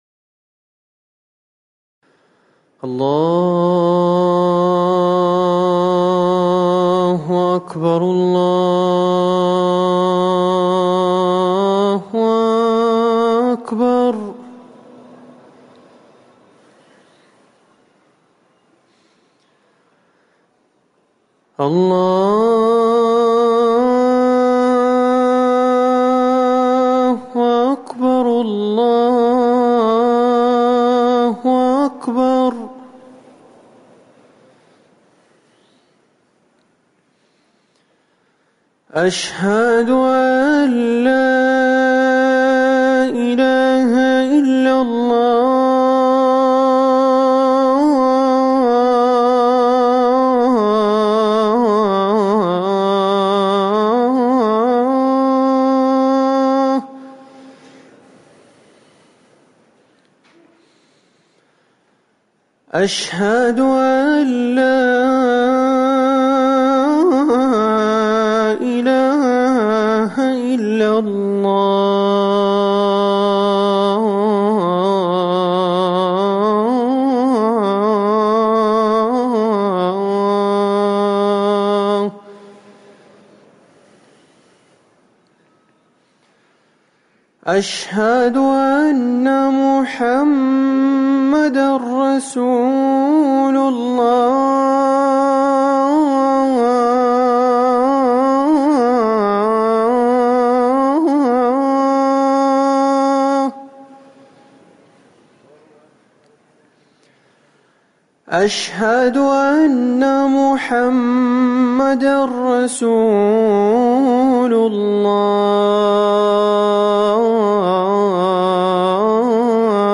أذان العصر
تاريخ النشر ٢٣ محرم ١٤٤١ هـ المكان: المسجد النبوي الشيخ